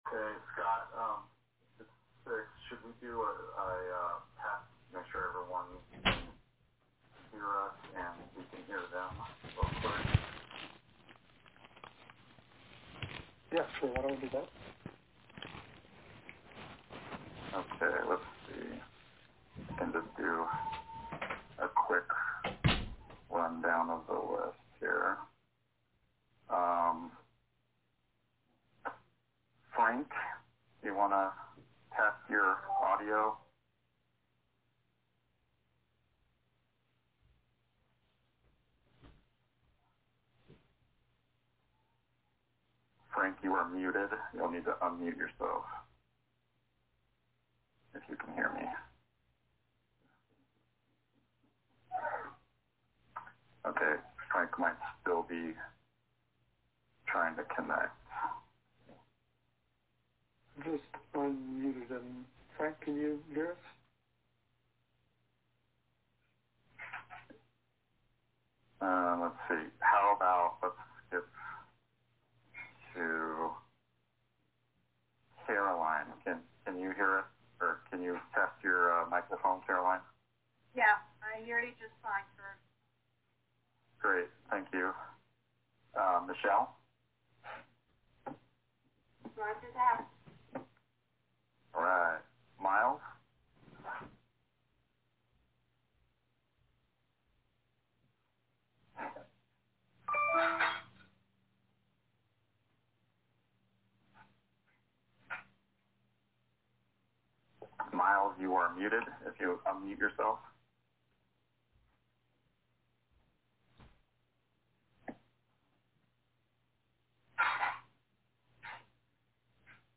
On May 14 2020 the steering committee for the East Central Area met .